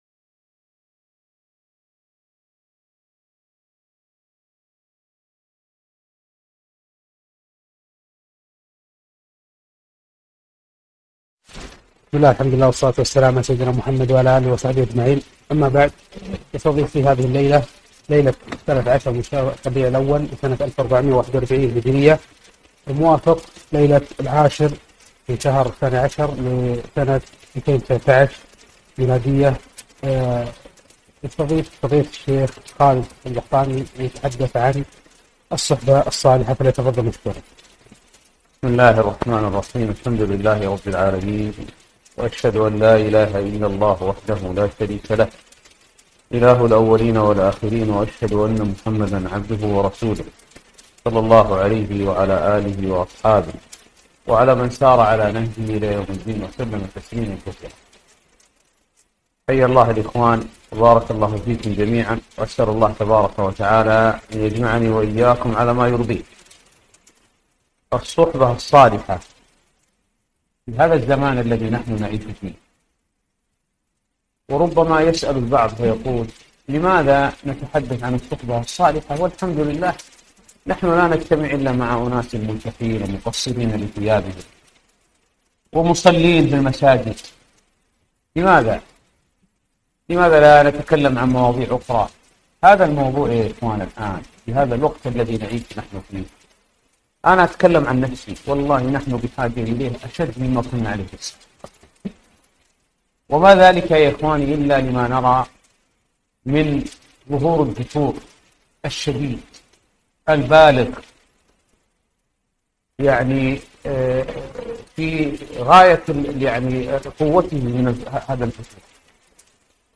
محاضرة - الصحبة الصالحة